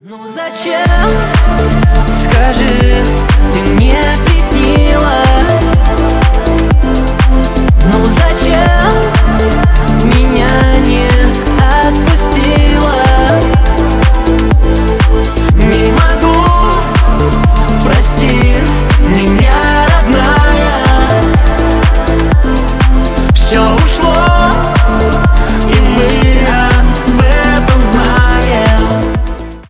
душевные